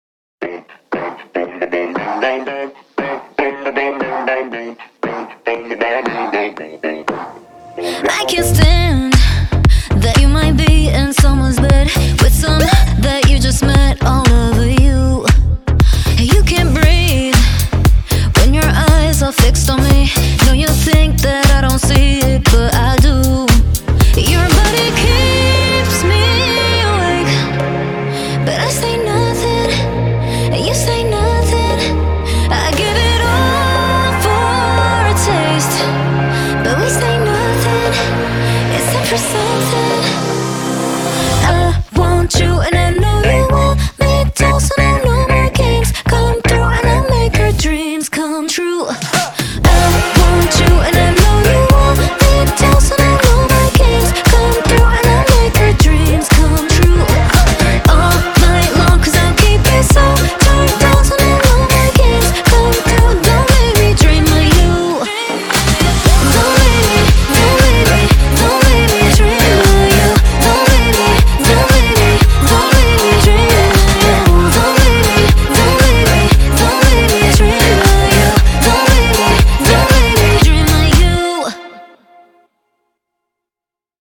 BPM117
Audio QualityMusic Cut